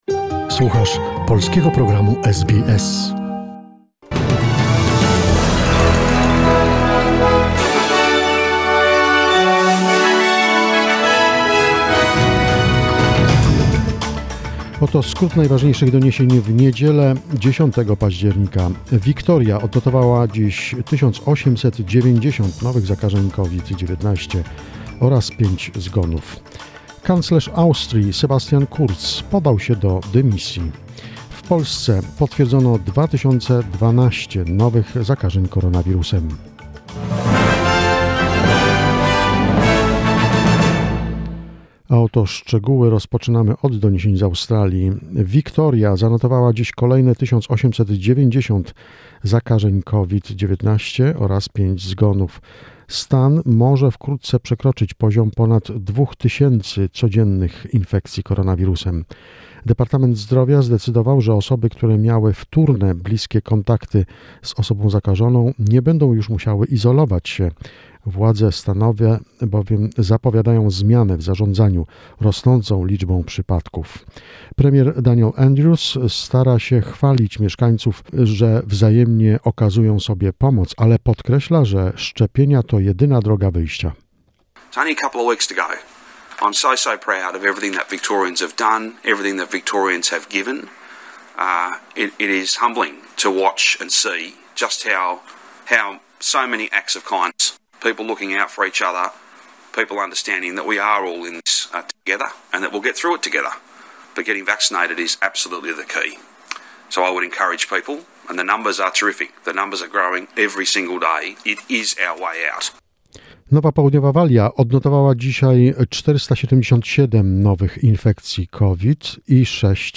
SBS News in Polish, 10 October 2021